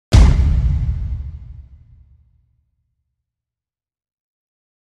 Heavy stompsound effect